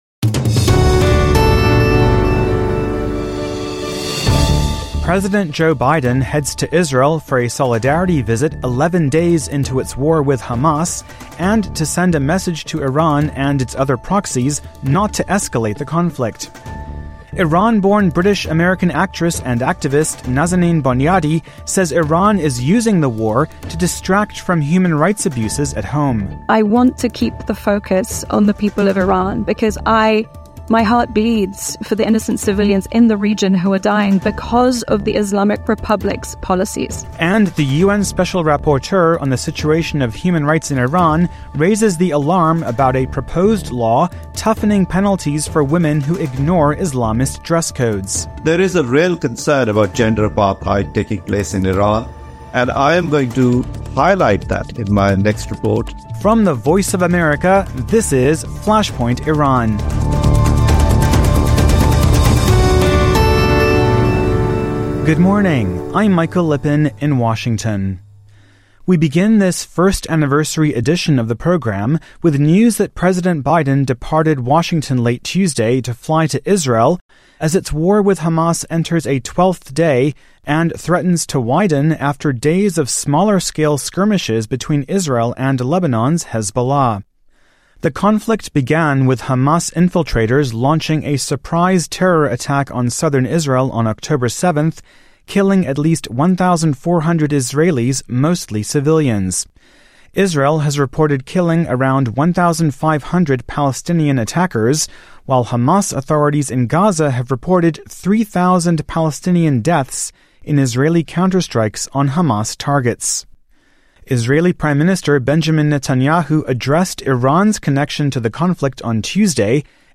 Leaders of Israel, Iran and the United States on Tehran’s connection to Israel-Hamas war that began October 7 with Hamas' attack on southern Israel. Iran-born British American actress and activist Nazanin Boniadi and United Nations Special Rapporteur on situation of human rights in Iran Javaid Rehman speak to VOA at the National Union for Democracy in Iran conference on how to end impunity for Tehran’s rights violations against the Iranian people.